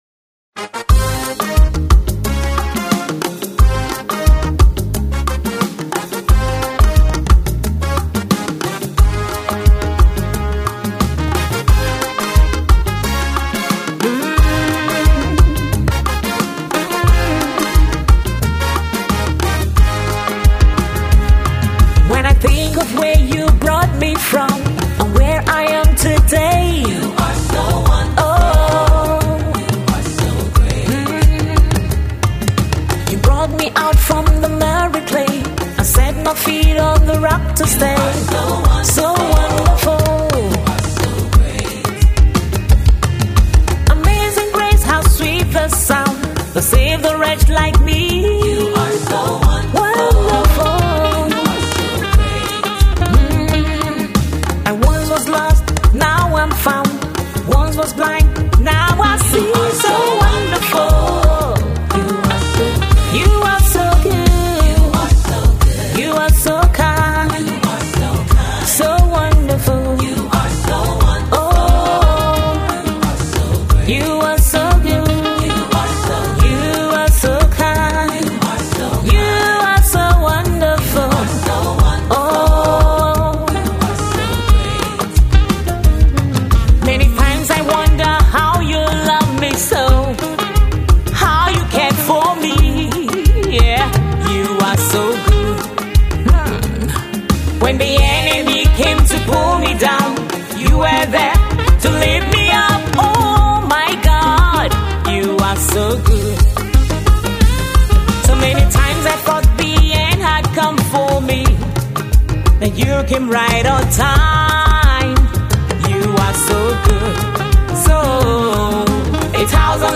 is a song of Praise and thanksgiving to God ..